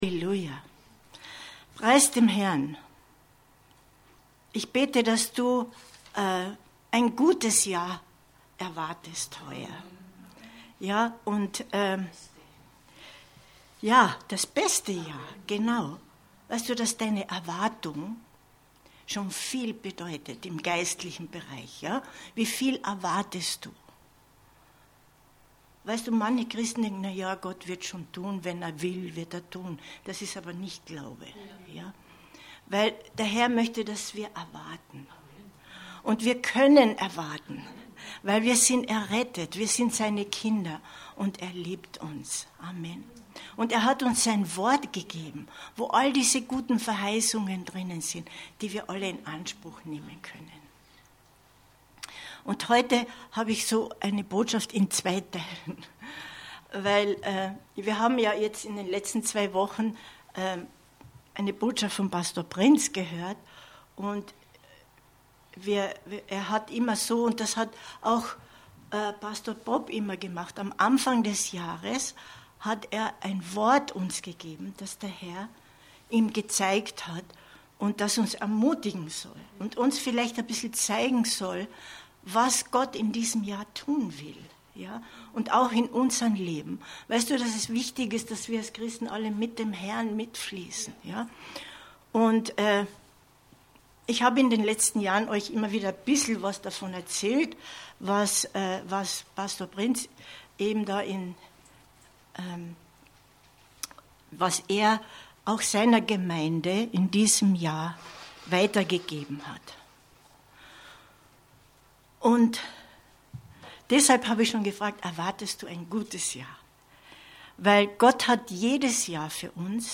Info Info Leben unter dem Schutz Gottes 22.01.2023 Predigt herunterladen